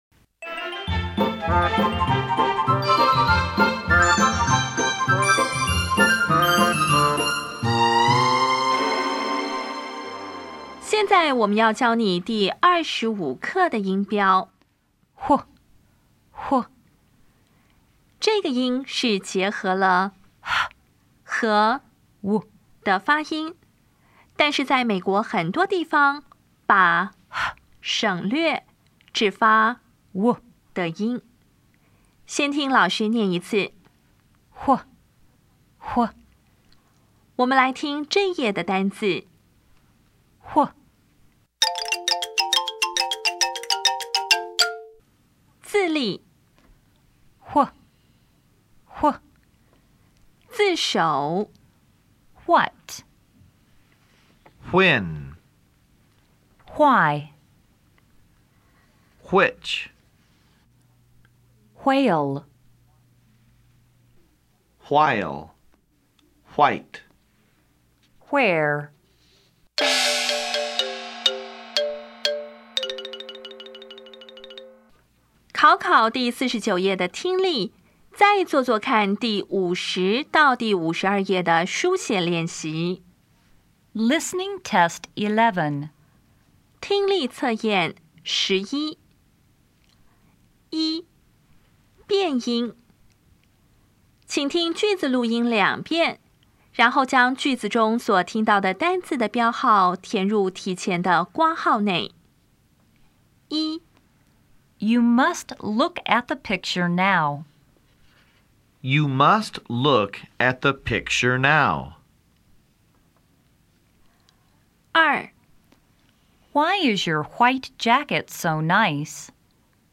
当前位置：Home 英语教材 KK 音标发音 子音部分-1: 无声子音 [hw]
音标讲解第二十五课
* 以上各个例字中的[hw]音
在美国有些地区，念成[w]的音。